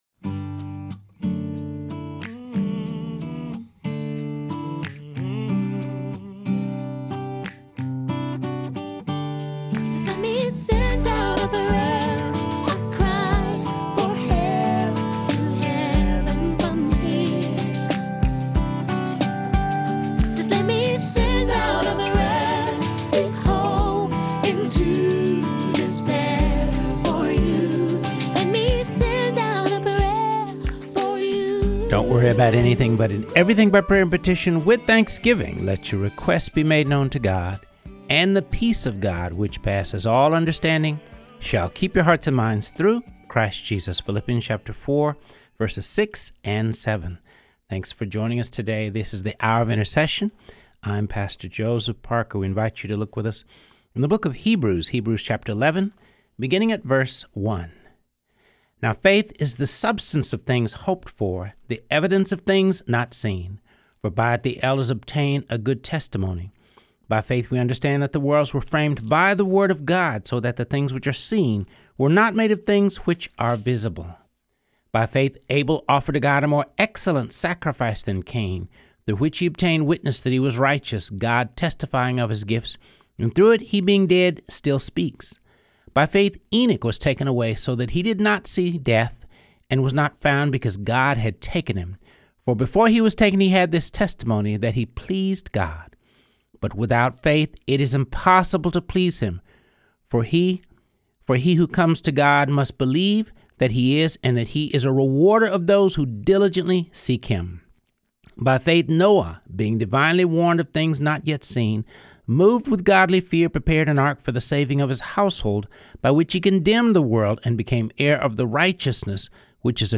reading through the Bible.